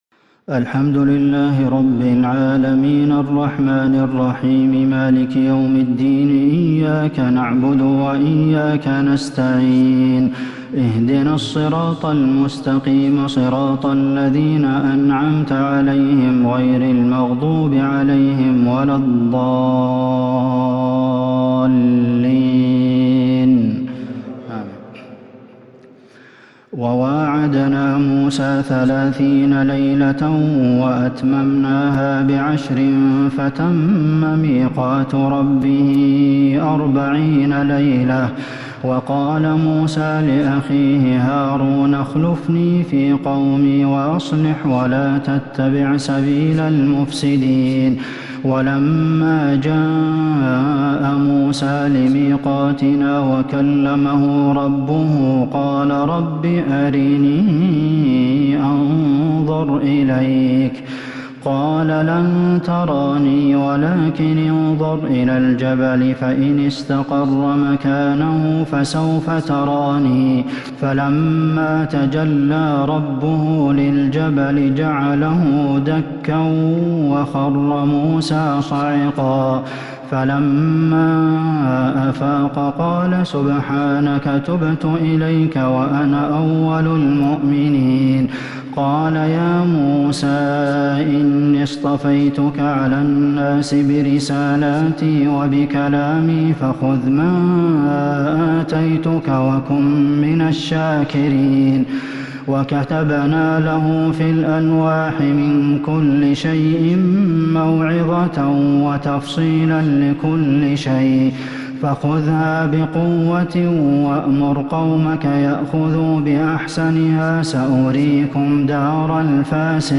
تراويح ليلة 12 رمضان 1442 من سورة الأعراف (142-200) Taraweeh 12st night Ramadan 1442H - Surah Al-A'raf > تراويح الحرم النبوي عام 1442 🕌 > التراويح - تلاوات الحرمين